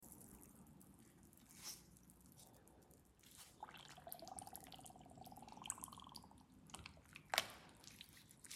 Pink Pasting Gym Chalk Sound Effects Free Download